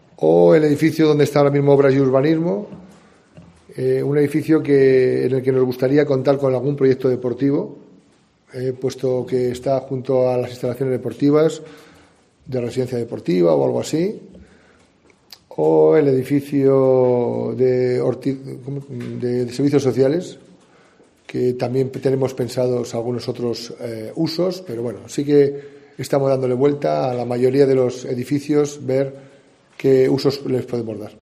José Mazarías, alcalde de Segovia, sobre el futuro de edificios municipales